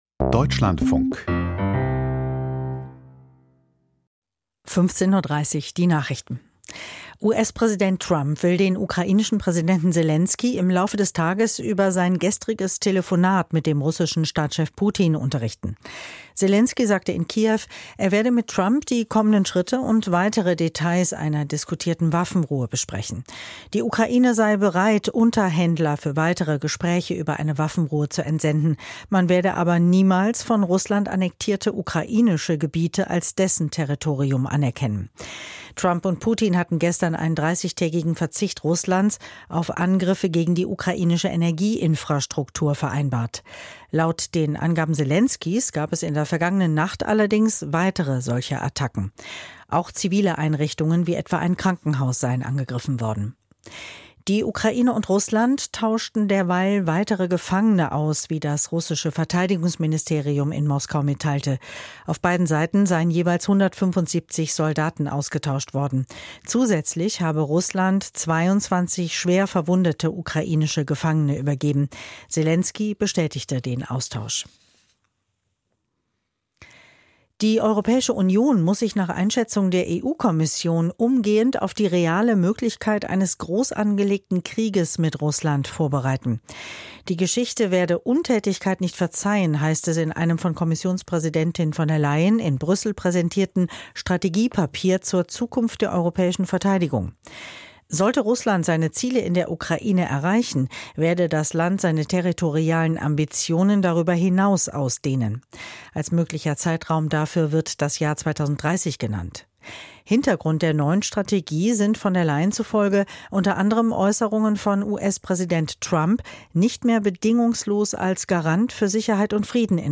Die Deutschlandfunk-Nachrichten vom 19.03.2025, 15:30 Uhr